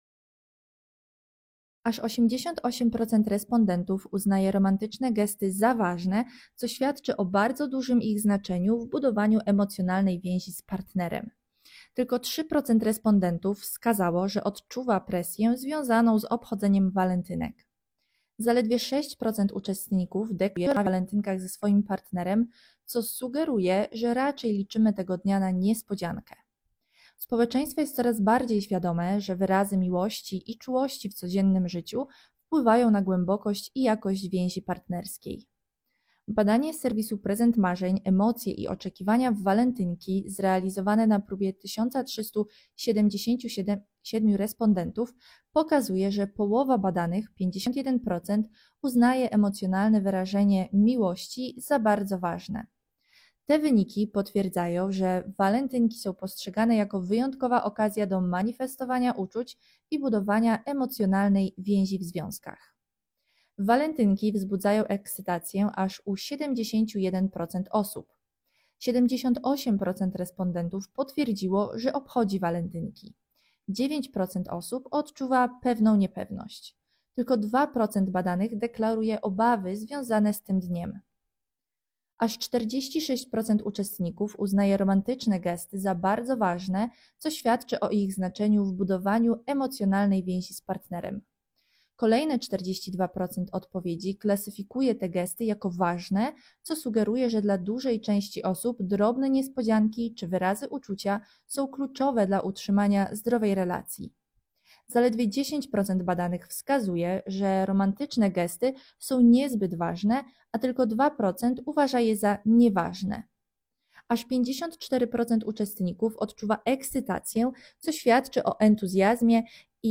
sonda_walentynki_2026.m4a